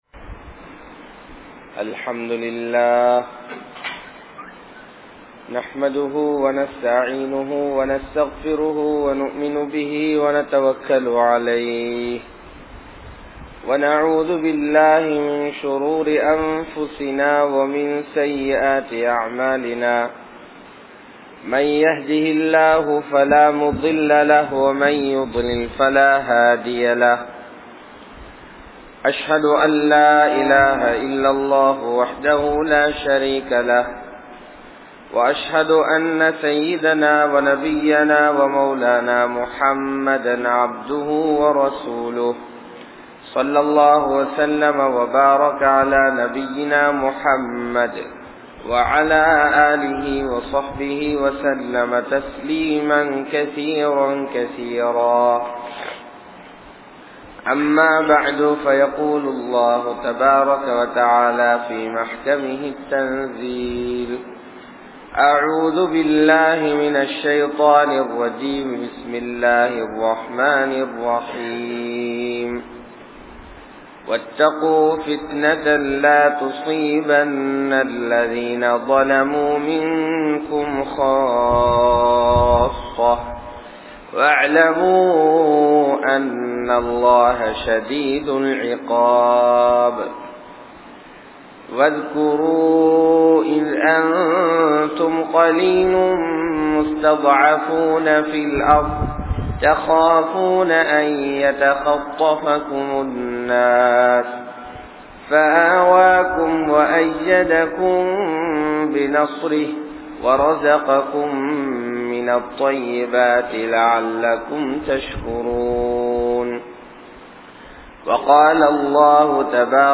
Dhajjaalin Attooliyangal (தஜ்ஜாலின் அட்டூழியங்கள்) | Audio Bayans | All Ceylon Muslim Youth Community | Addalaichenai